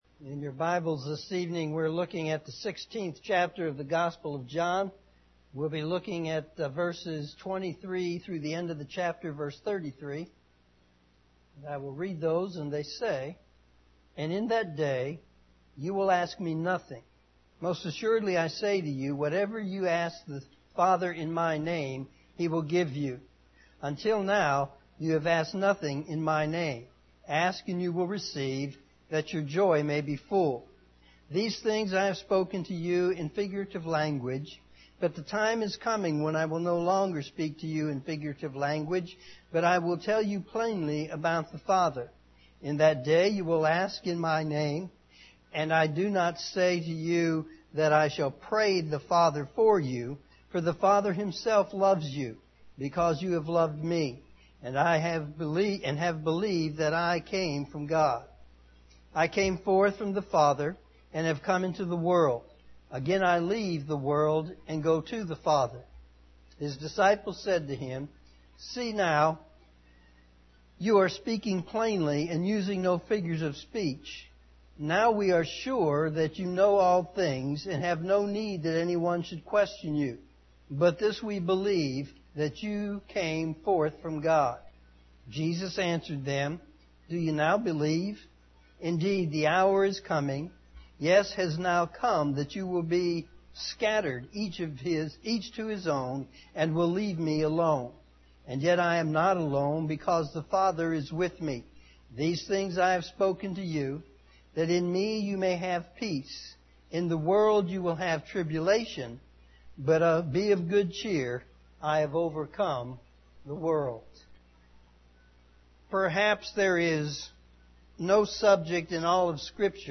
evening service
sermon9-16-18pm.mp3